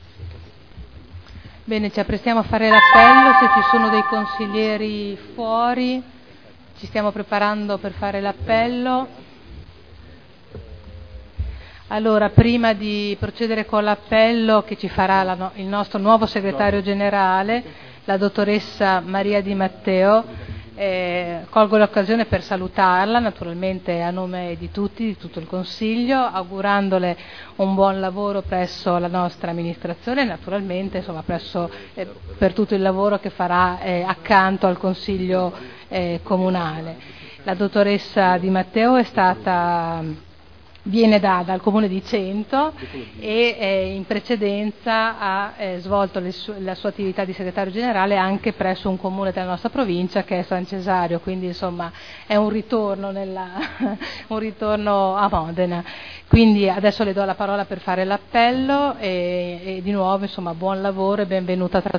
Seduta del 05/12/2011. Il Presidente Caterina Liotti presenta il nuovo Segretario.